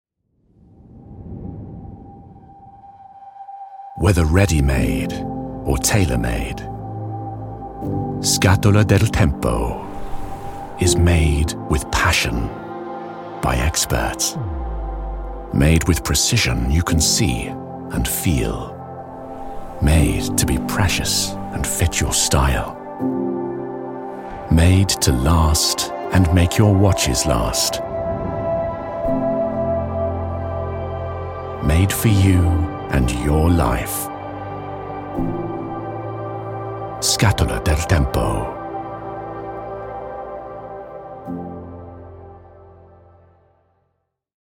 British Demo